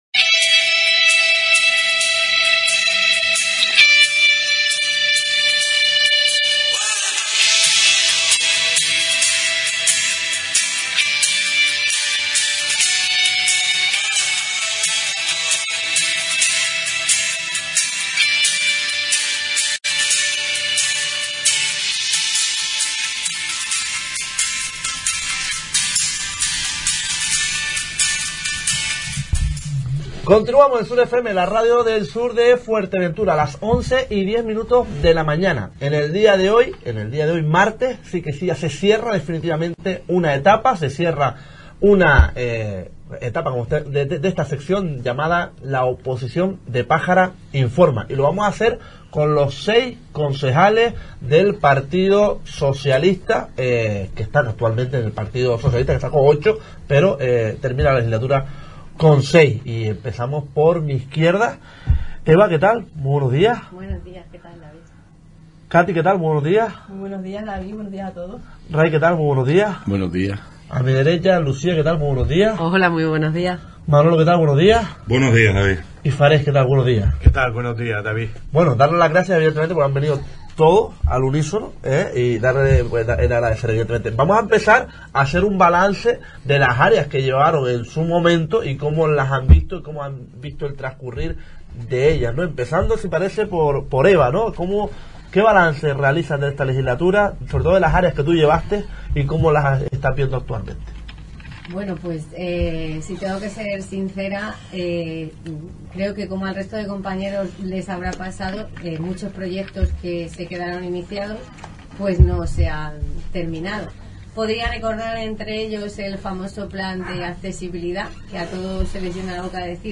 En el día de hoy terminamos la sección de La Oposición de Pájara Informa y nos visitaron todos los concejales electos del PSOE en Pájara en la oposición.